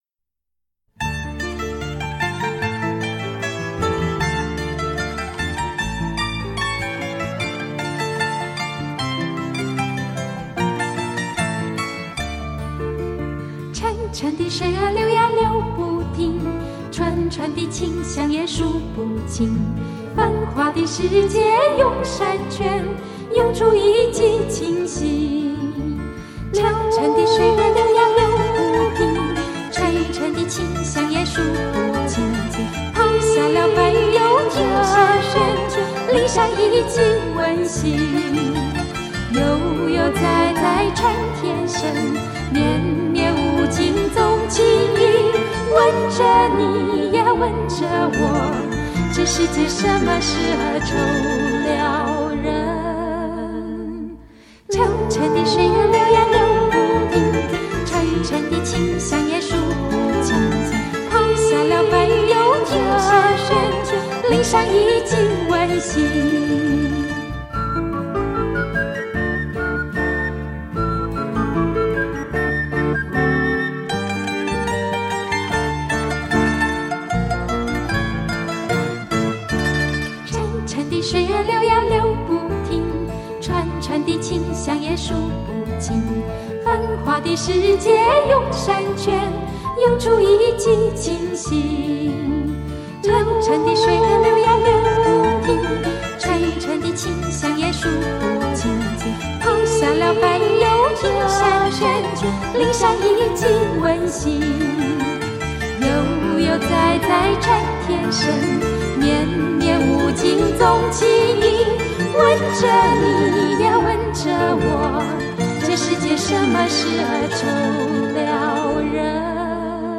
以清脆的曼驼铃为主要配器，模拟铮淙的流水跫音，而柔美的合声与之唱和，带给听者犹如山间赏景的写意感受